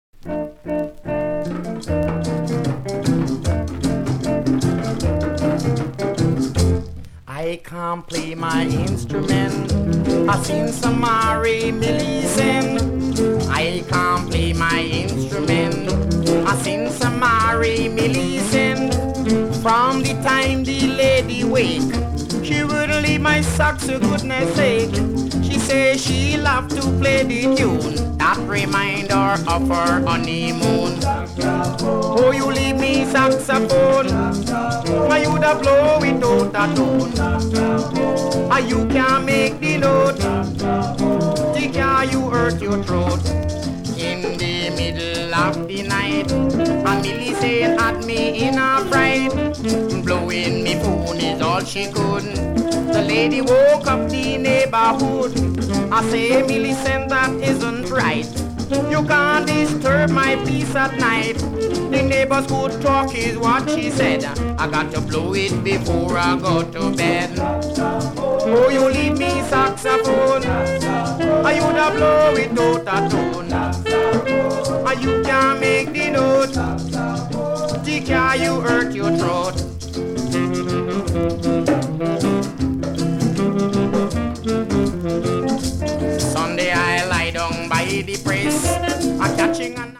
ジャマイカのカリプソ”メント”のグループ